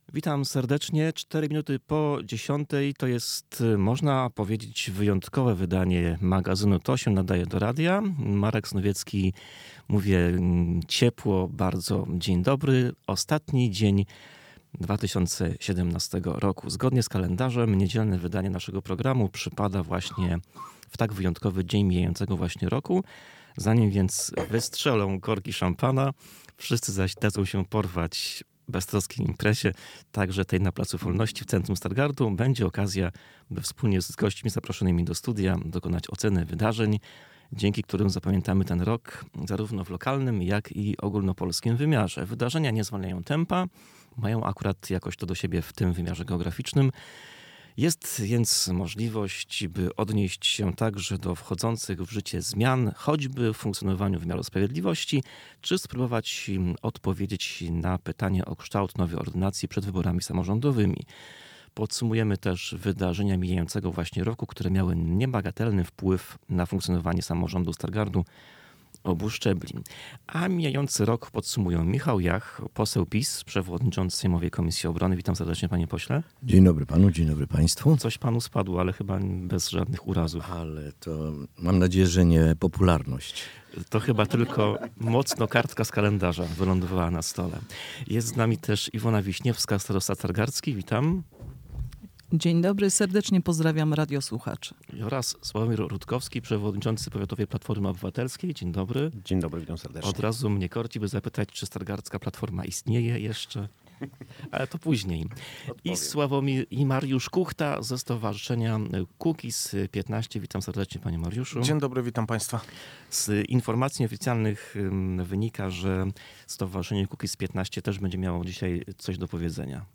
Zanim więc wystrzelą korki szampana, wszyscy zaś dadzą się porwać beztroskiej imprezie- także tej na placu Wolności w Centrum Stargardu- będzie okazja, by wspólnie z gośćmi zaproszonymi do studia dokonać oceny wydarzeń, dzięki którym zapamiętamy ten rok, zarówno w lokalnym jak i ogólnopolskim wymiarze.